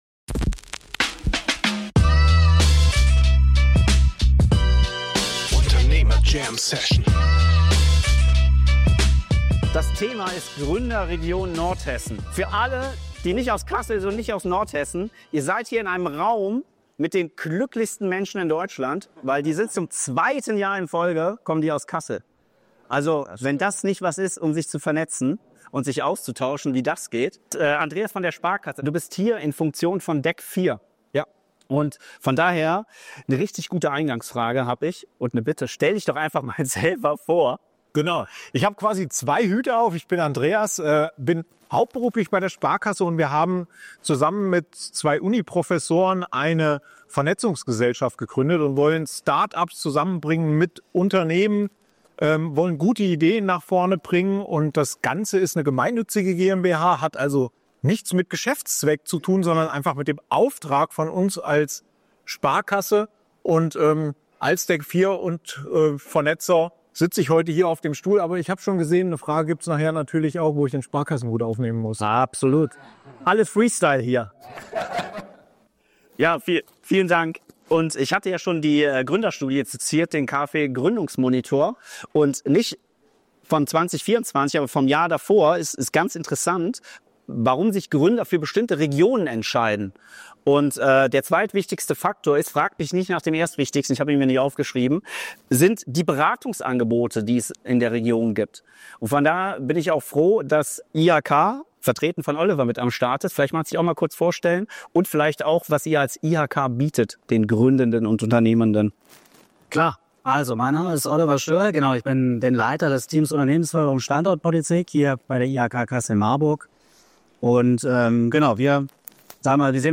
Im Rahmen der ersten Beats & Business-Veranstaltung am 14.11.2025 im Voyage in Kassel – 3 Jahre Unternehmer Jam-Session – haben wir einen Podcast vor Publikum zu den Themen Gründen und Unternehmertum im Allgemeinen sowie Gründen in Nordhessen im Speziellen aufgenommen.